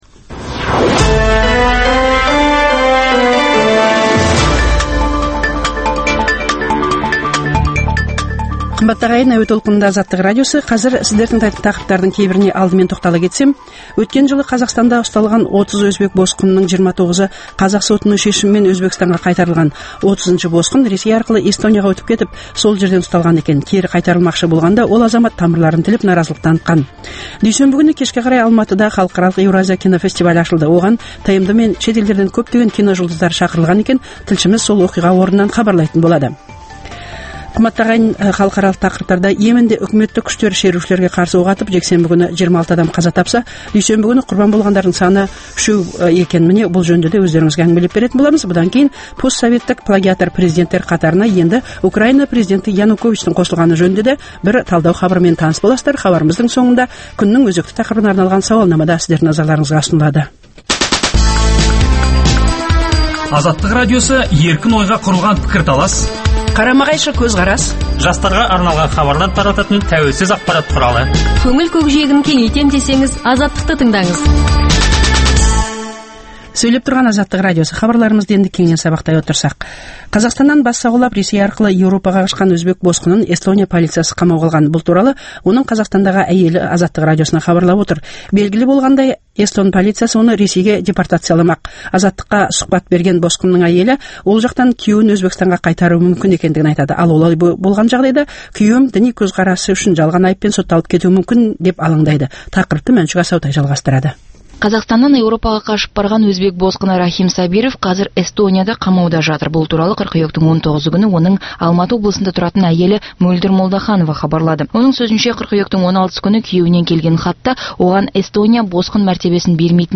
Дүйсенбі күні кешке қарай Алматыда халықаралық «Еуразия» кинофестивалі ашылды, оған ТМД мен шетелдерден көптеген киножұлдыздар шақырылған екен. Тілшіміз сол оқиға орнынан хабарлайды.